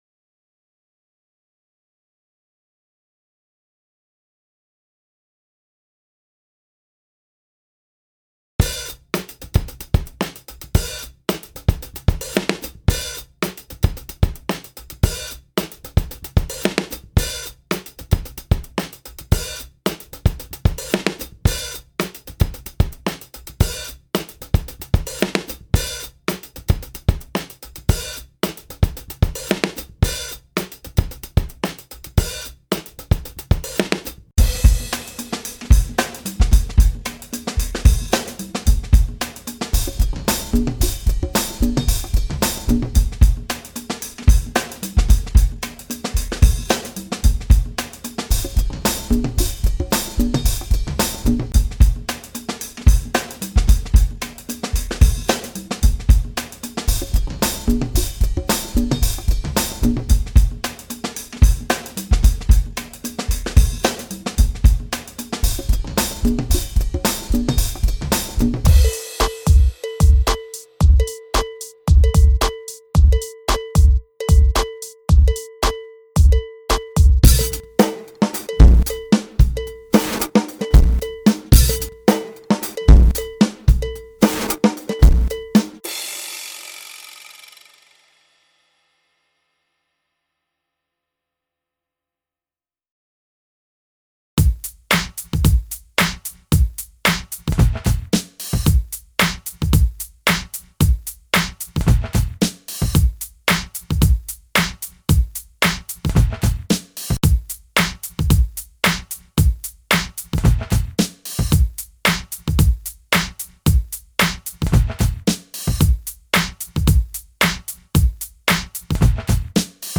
I added some drums to fill it out a bit, which points out my wandering rhythm.
july-percussion.mp3